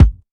Kicks
bdrum070.wav